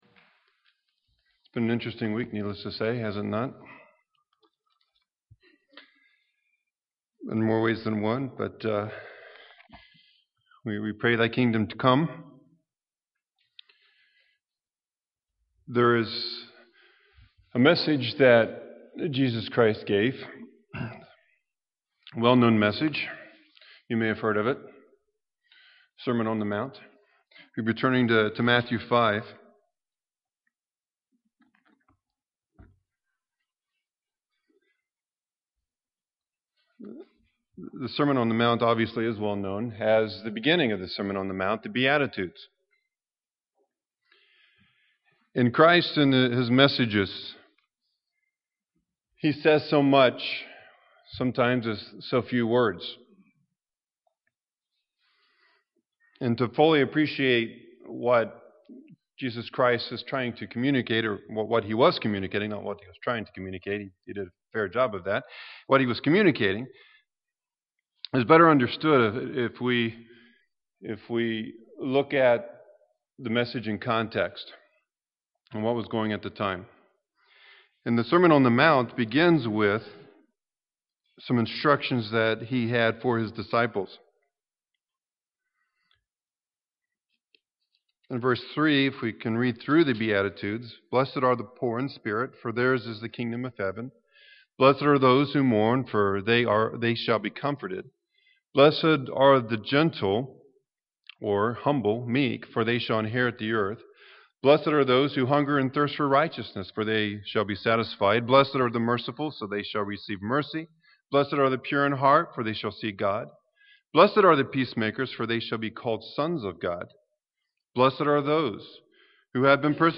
The Beatitudes start Jesus Christ's teaching of the disciples. This sermon series reviews these teachings, and application in our lives.
Given in Milwaukee, WI